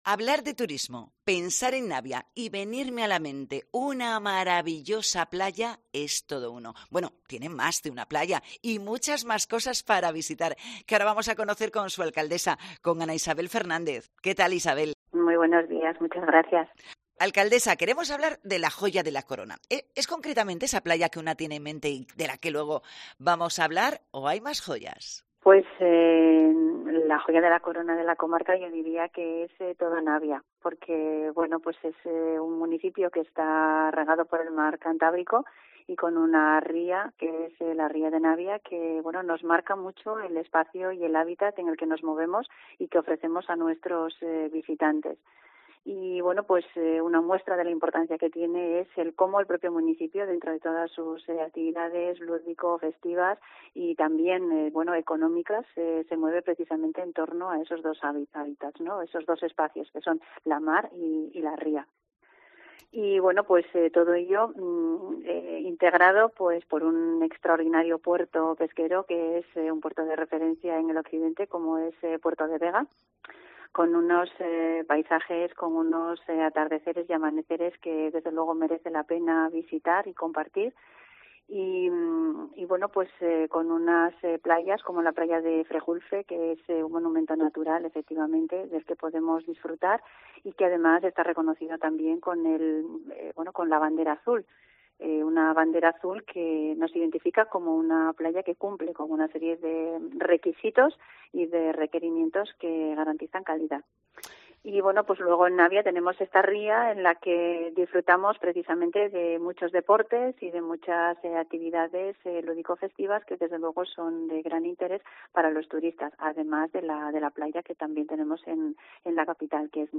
En el especial de COPE Asturias desde FITUR 2024, hablamos con la alcaldesa del concejo, Ana Isabel Fernández: "Aquí puedes combinar un día de playa con gastronomía y cultura"
FITUR 2024: Entrevista a Ana Isabel Fernández, alcaldesa de Navia